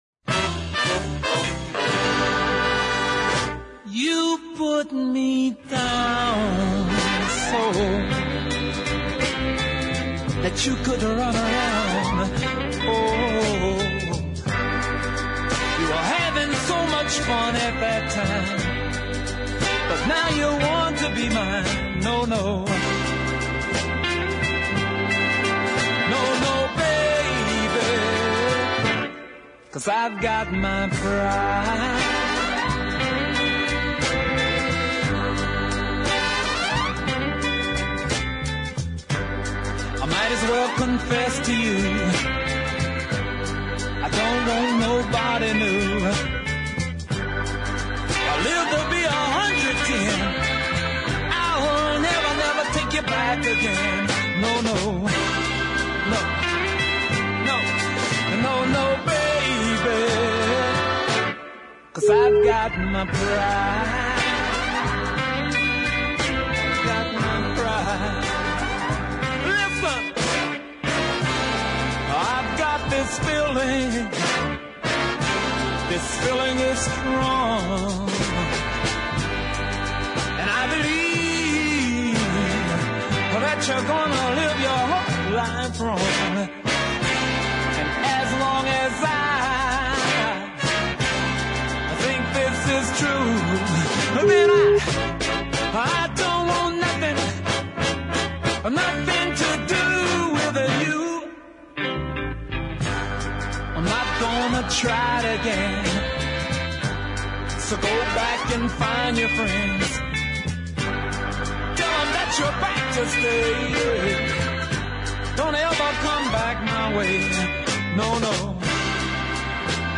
deep soul cut